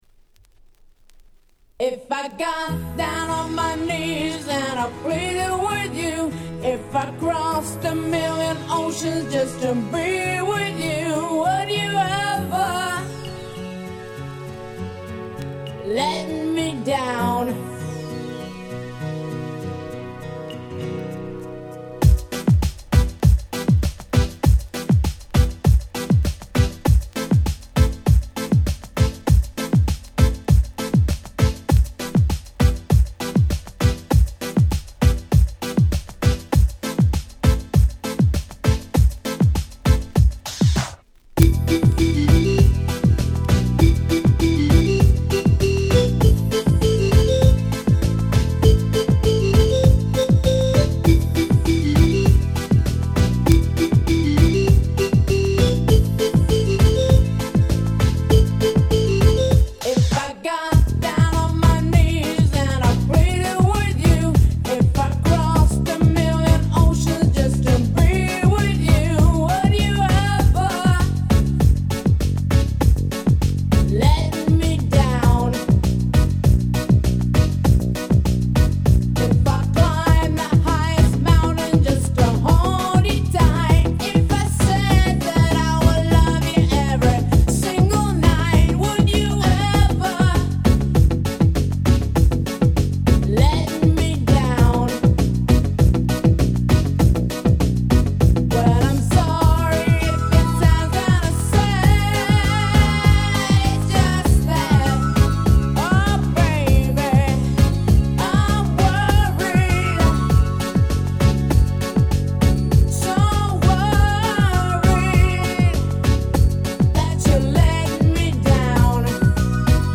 94' Nice Ground Beat !!